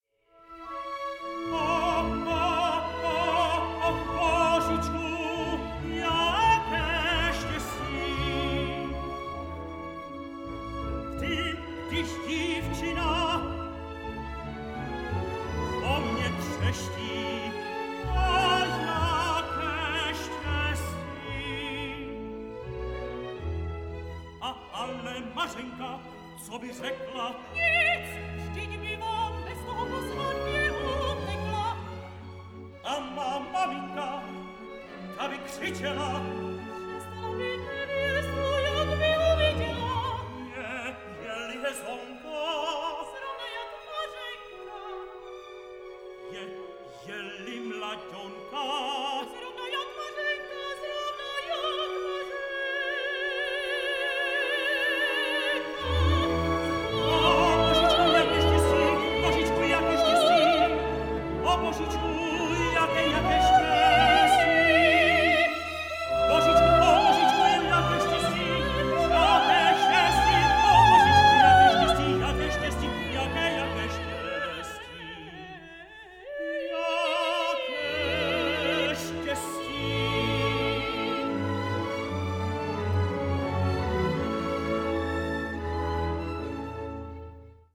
sings them with clear, pillowy tones